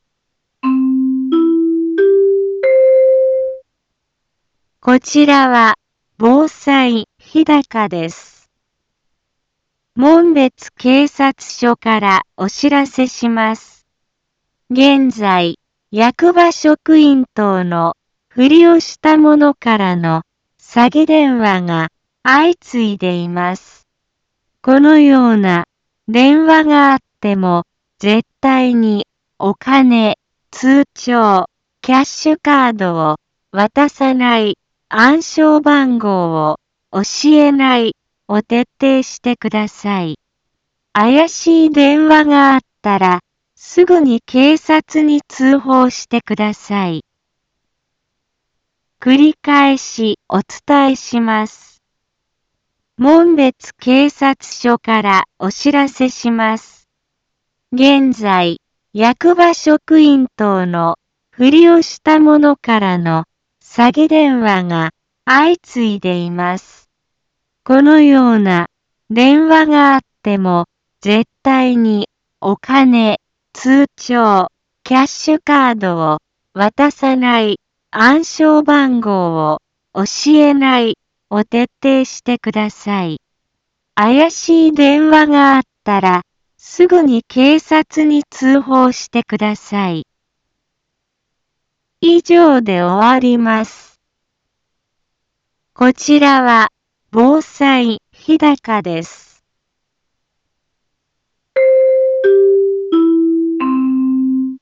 一般放送情報
Back Home 一般放送情報 音声放送 再生 一般放送情報 登録日時：2021-01-18 15:03:41 タイトル：特殊詐欺に関するお知らせ インフォメーション： こちらは、防災日高です。